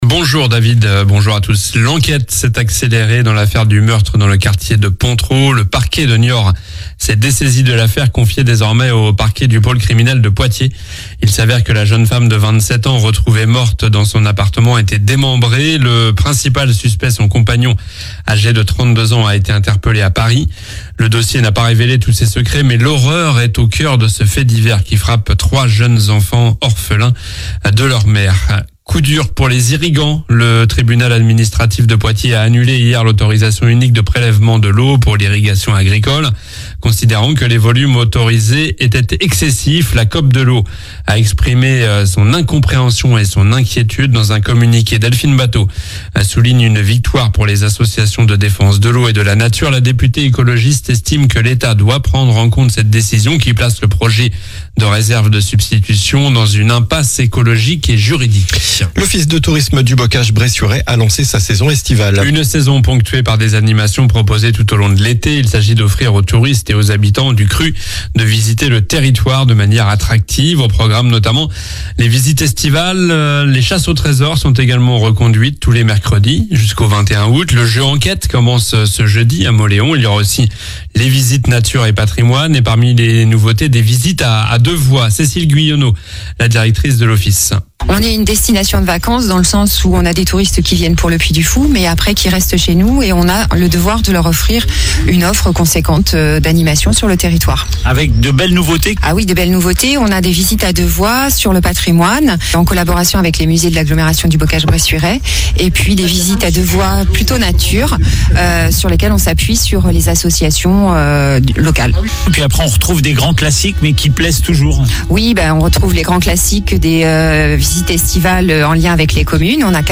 Journal du mercredi 10 juillet (matin)